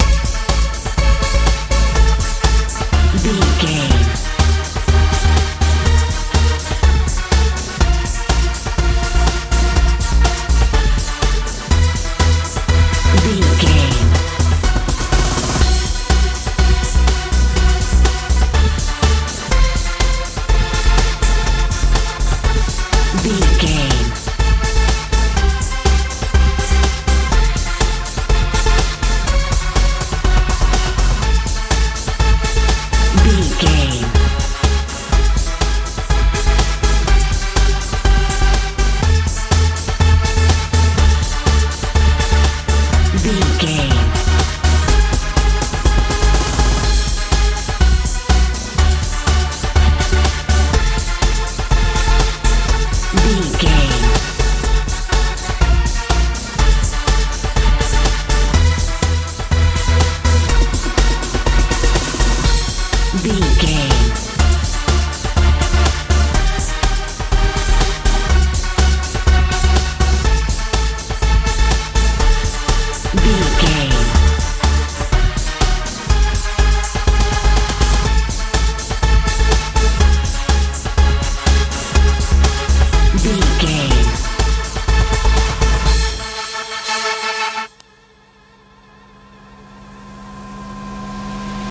euro dance feel
Ionian/Major
C♯
energetic
bass guitar
synthesiser
drums
tension
suspense